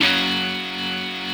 rockerChordF#5.wav